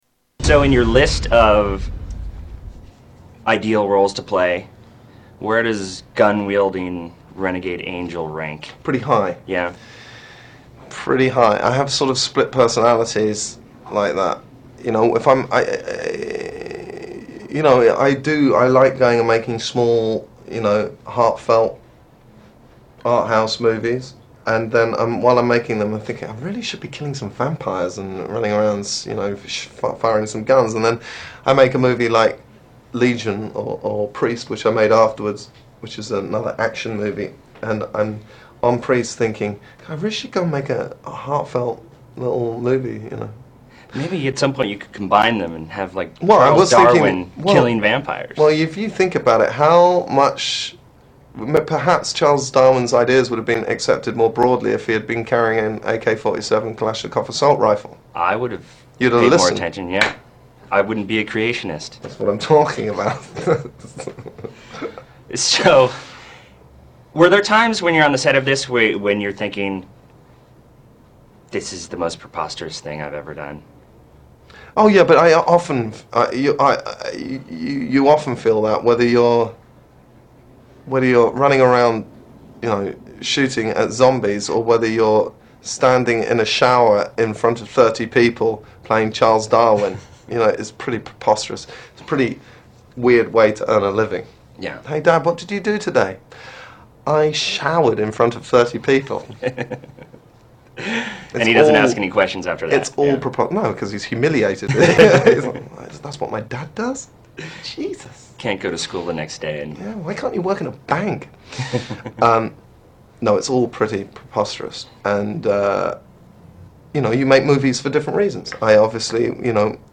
Paul Bettany Interview